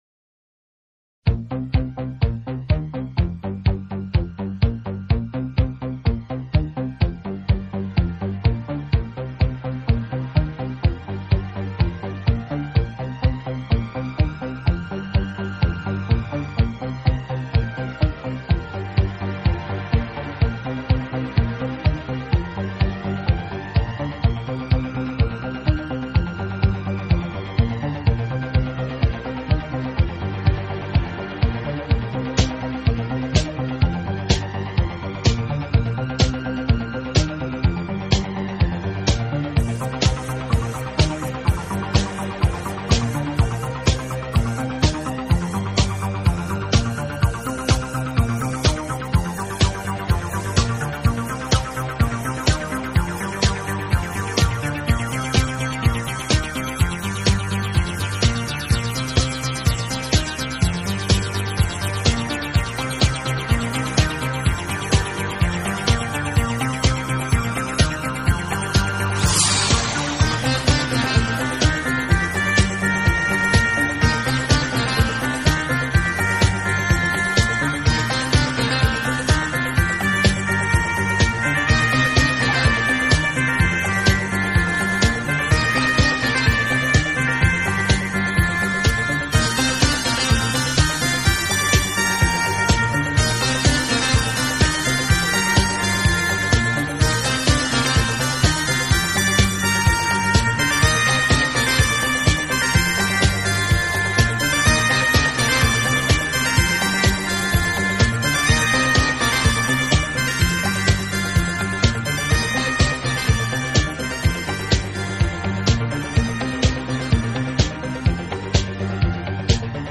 Soundtrack, Pop, Disco